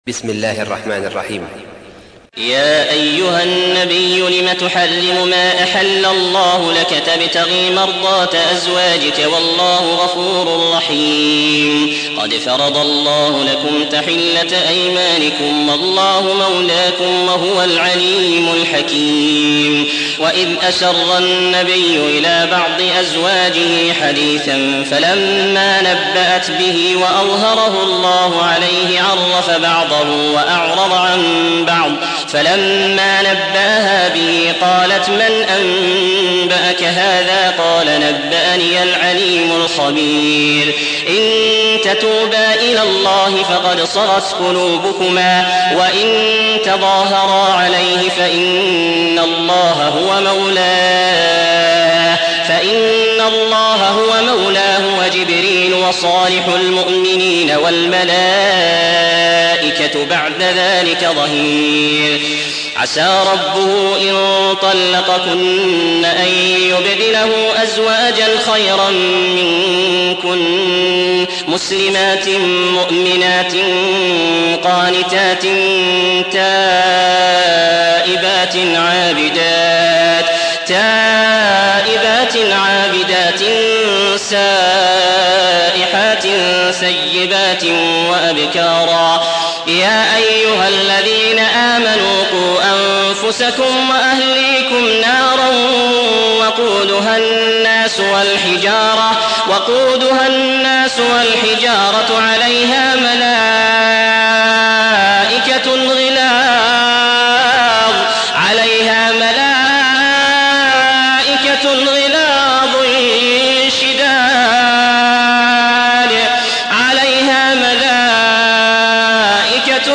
تحميل : 66. سورة التحريم / القارئ عبد العزيز الأحمد / القرآن الكريم / موقع يا حسين